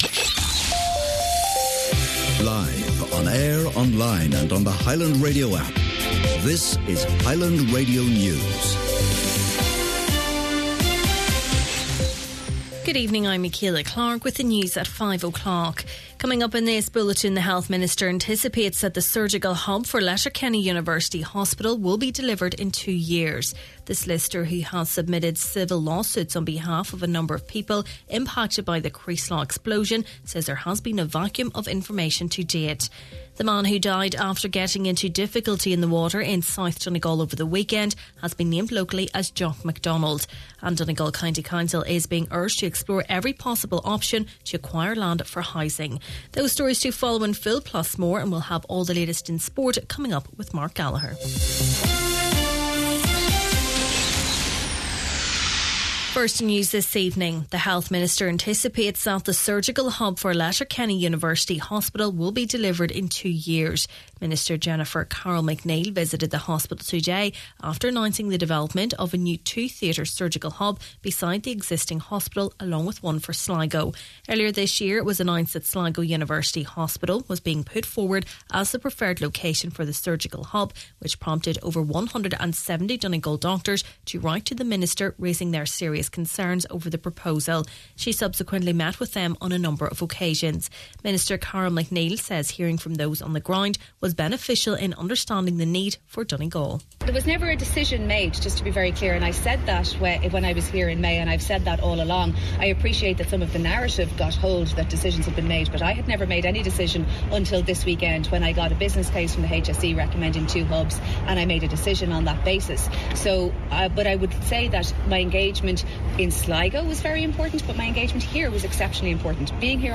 Main Evening News, Sport and Obituaries – Monday, July 28th